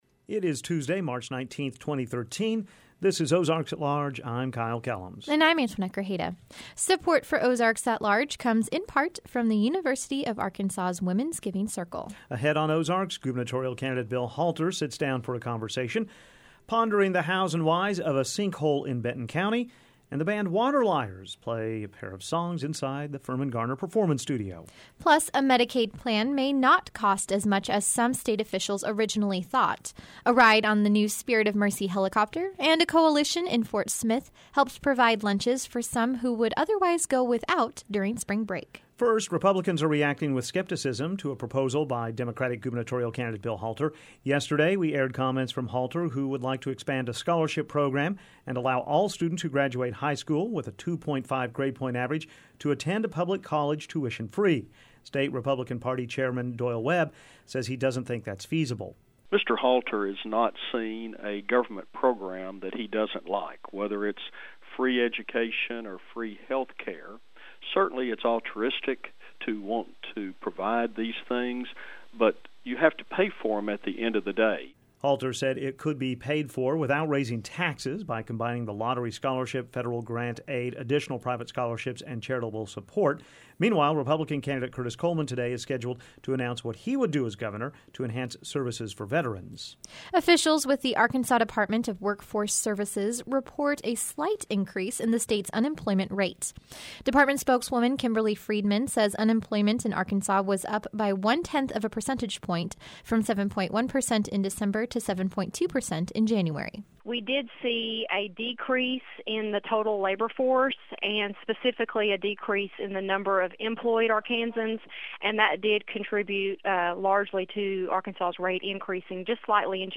Audio: OAL_03_19_13.mp3 Ahead on Ozarks: Gubernatorial candidate Bill Halter sits down for a conversation, pondering the hows and whys of a sinkhole in Benton County and the band Water Liars play a pair of songs inside the Firmin-Garner Performance Studio.